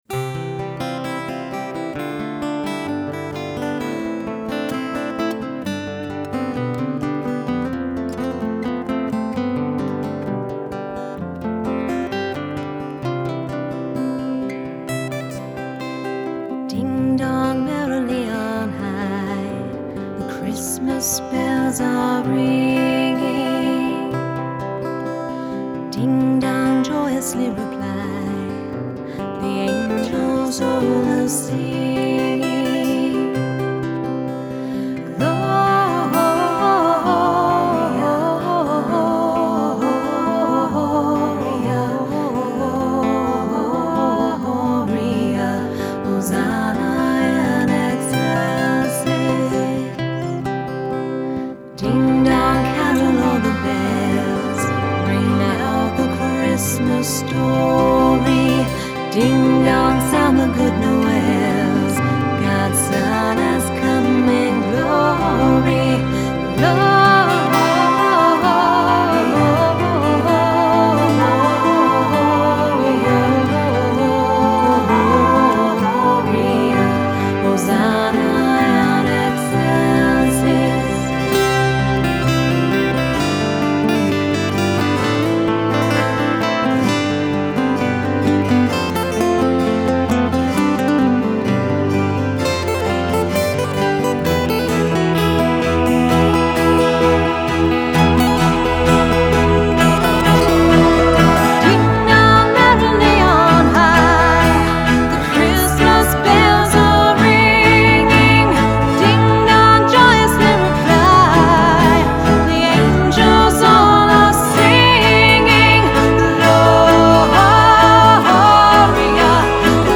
Жанр: фолк-рок
Genre: Folk, Rock